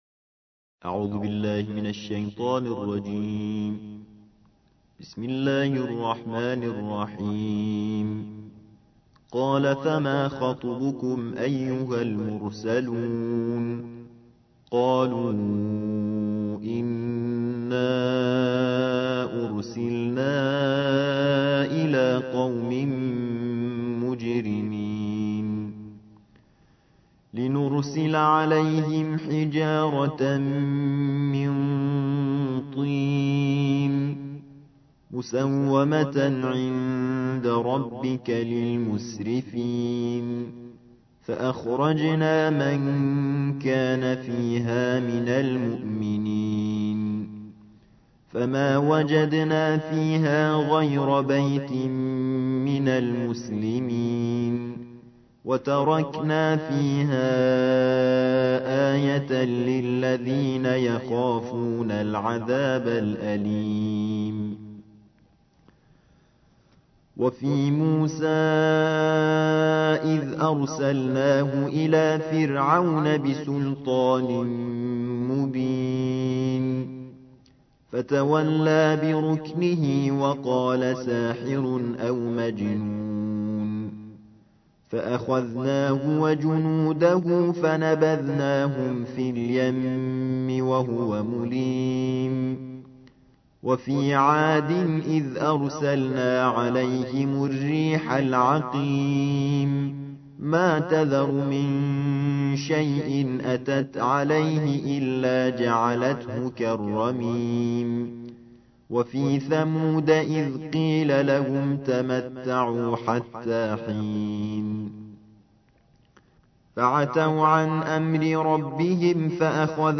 الجزء السابع والعشرون / القارئ